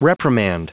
Prononciation du mot reprimand en anglais (fichier audio)
Prononciation du mot : reprimand